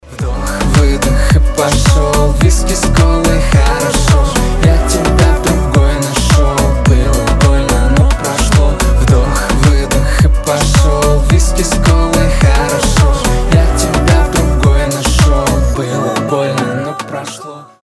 • Качество: 320, Stereo
мужской голос
дуэт